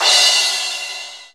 CYM XCHEEZ09.wav